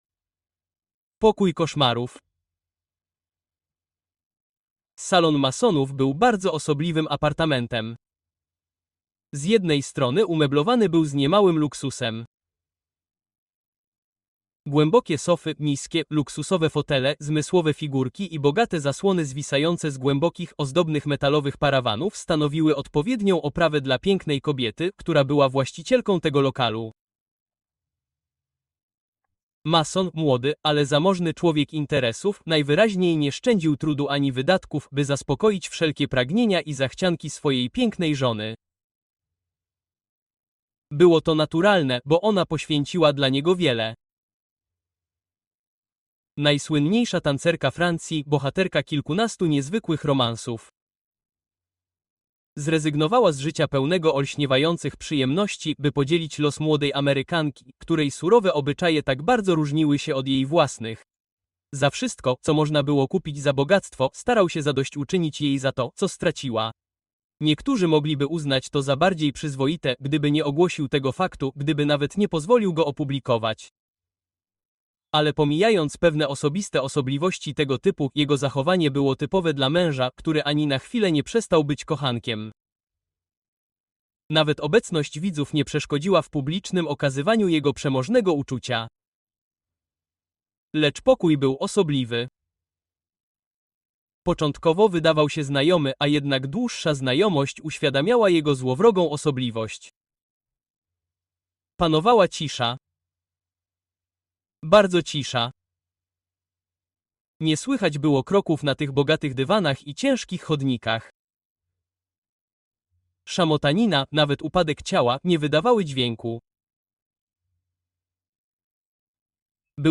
Three Broken Threads: Sherlock Holmes’ Deadly Clues (Audiobook)